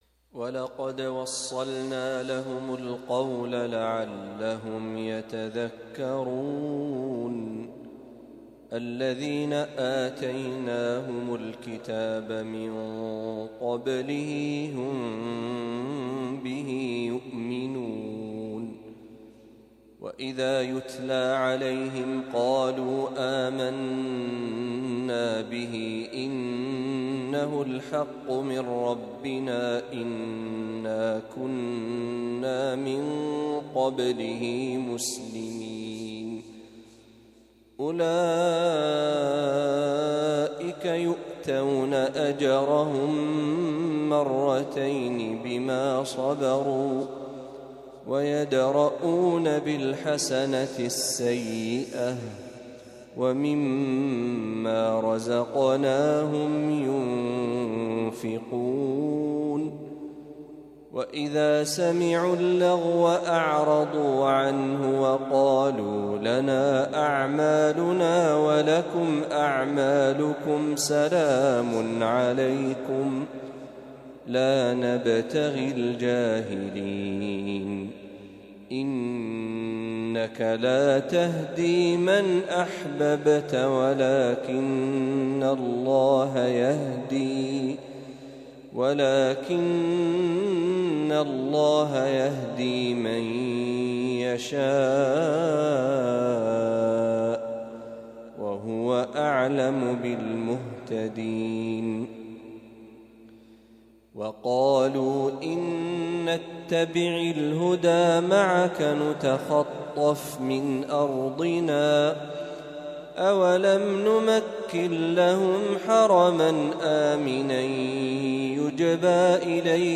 تلاوة لما تيسر من سورة القصص | فجر الأحد ١ محرم ١٤٤٦هـ > 1446هـ > تلاوات الشيخ محمد برهجي > المزيد - تلاوات الحرمين